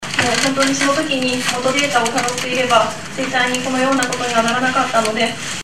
筆者は、2014年4月9日に行われた小保方晴子氏の記者会見を逆再生分析しているので、一部のリバース・スピーチを紹介しておきたい。